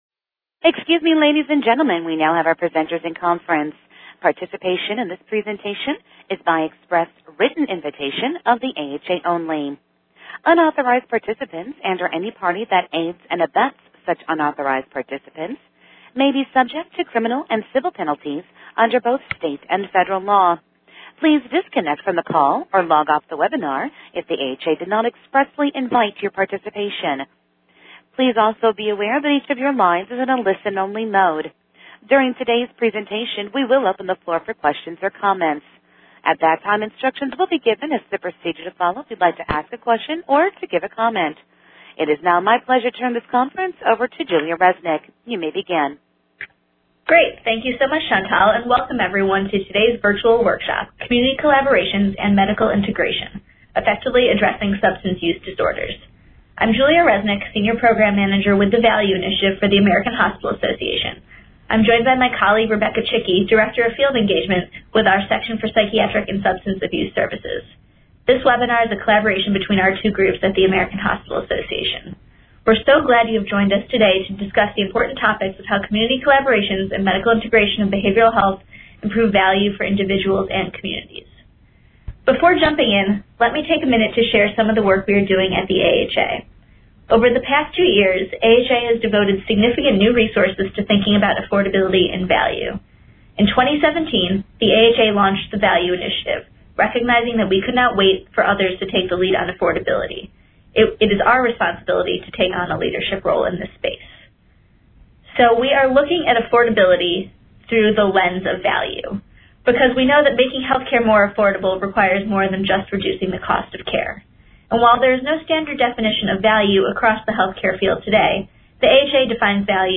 Audio Replay: Presbyterian Health Care’s Community Collaborations and Medical Integration: Effectively Addressing Substance Use Disorders | AHA Events